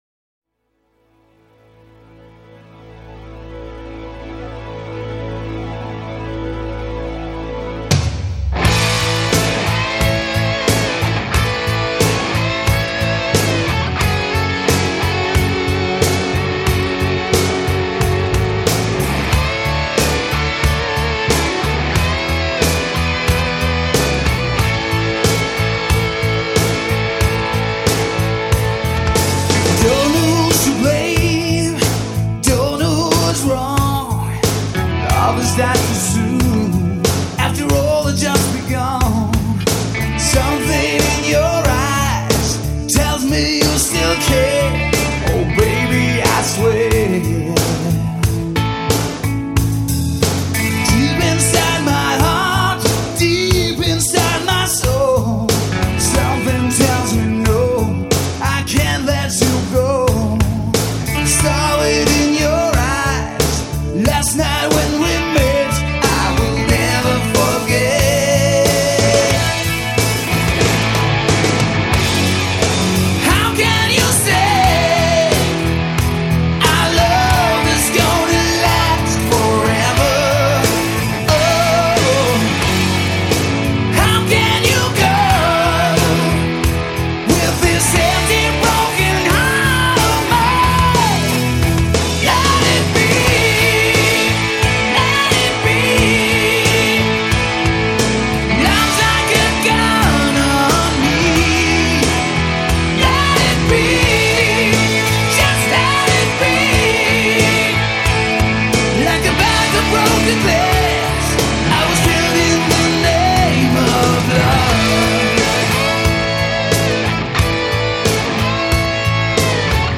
Жанр: Melodic Hard Rock